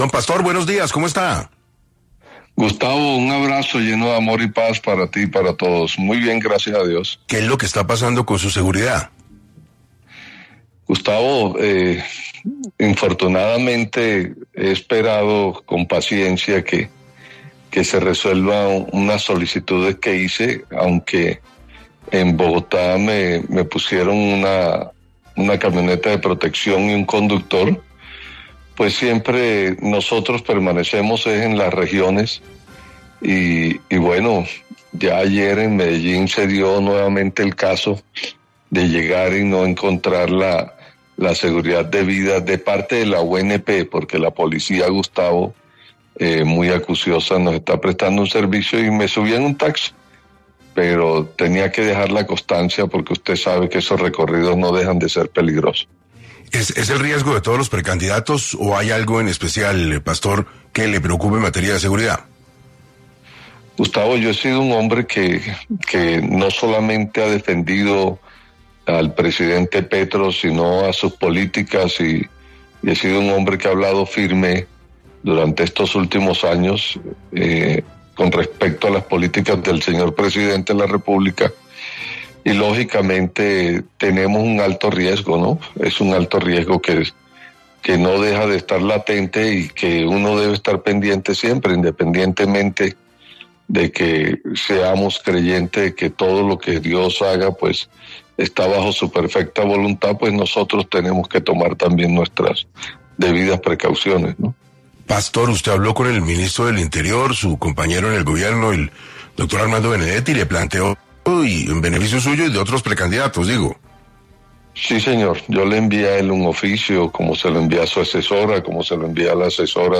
El precandidato presidencial habló en 6AM sobre su seguridad y las divisiones que hay entre Daniel Quintero y Gustavo Bolívar